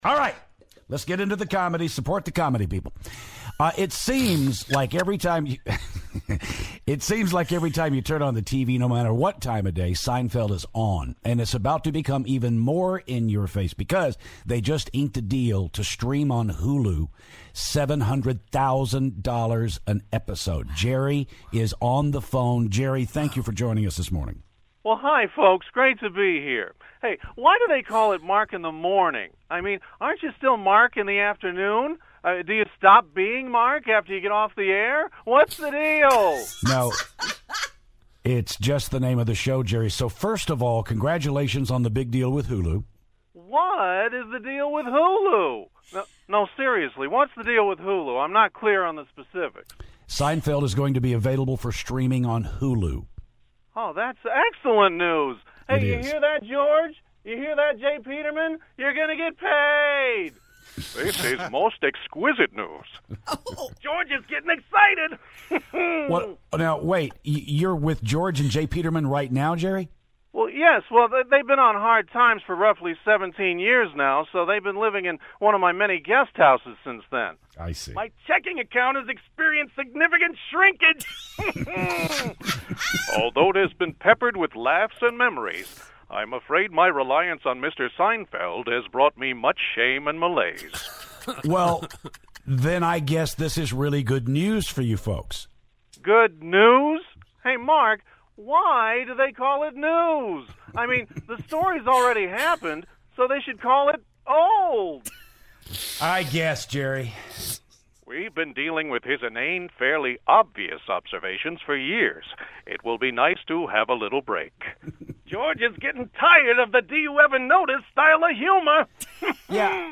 The guys take a call from Jerry Seinfeld...